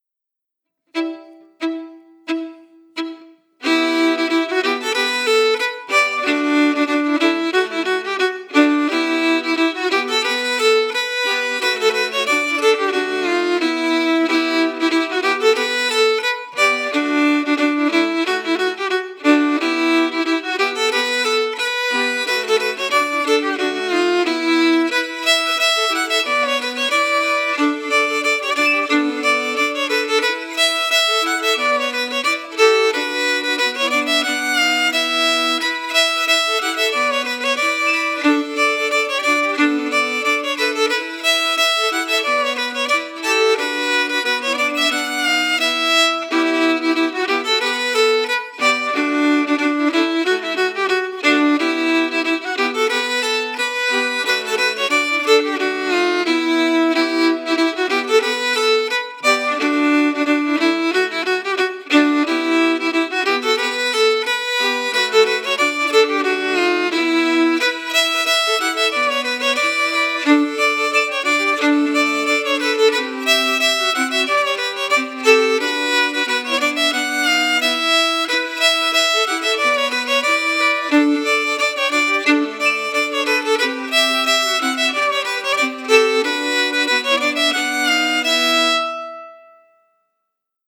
Key: Em
Form: March
Melody emphasis
Genre/Style: “Northern March”